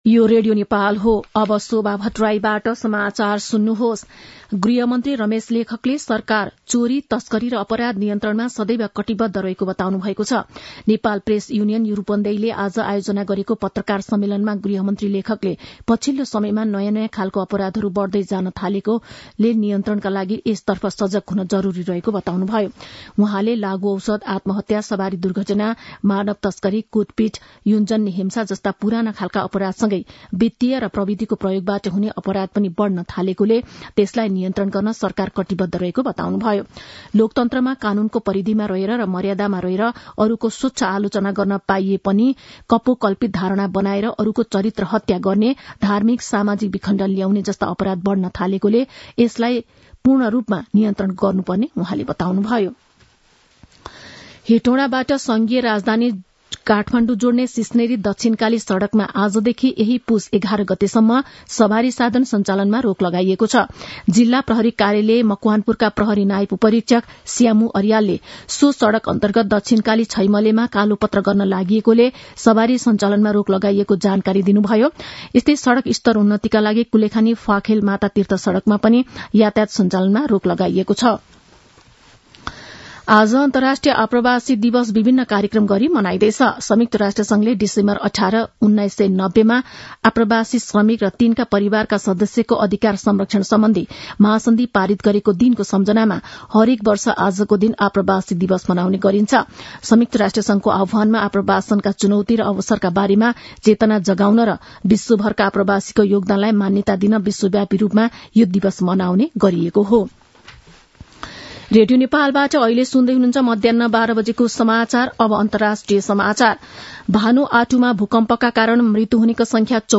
An online outlet of Nepal's national radio broadcaster
मध्यान्ह १२ बजेको नेपाली समाचार : ४ पुष , २०८१